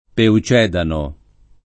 [ peu ©$ dano ]